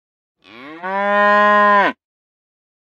moo.ogg.mp3